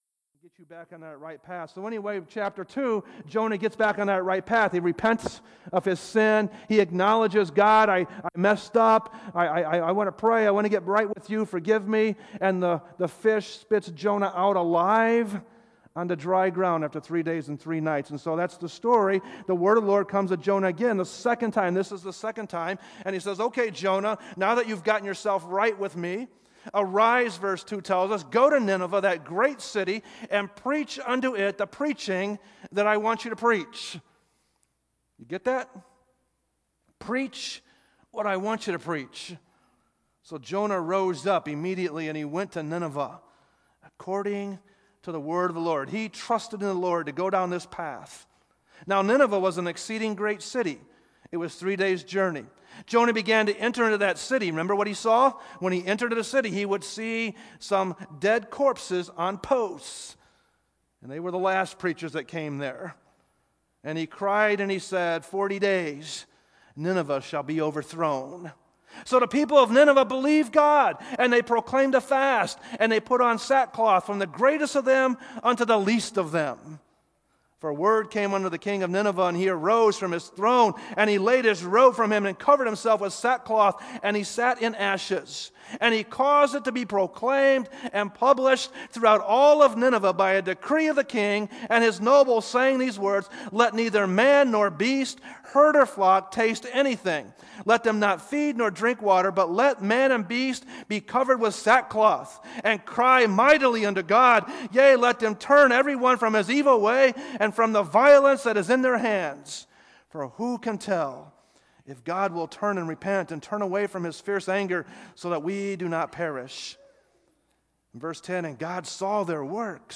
sermons preached at Grace Baptist Church in Portage, IN